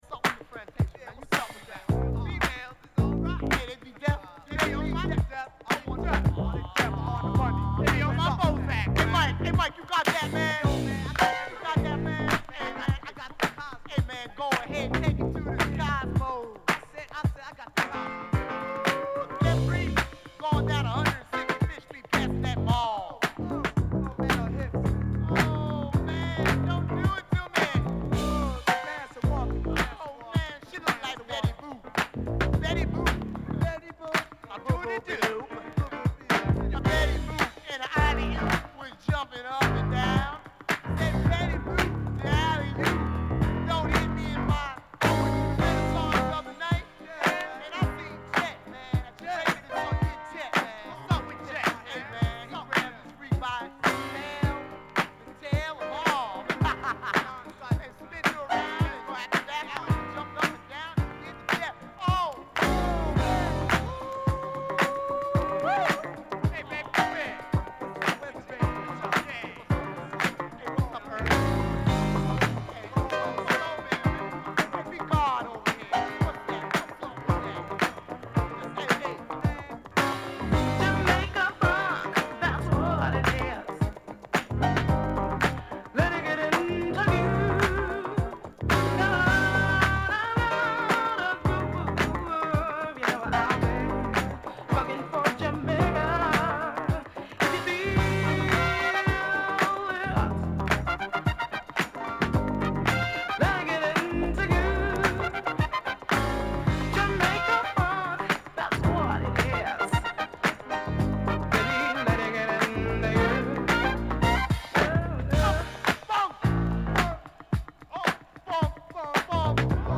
Soul Funk Disco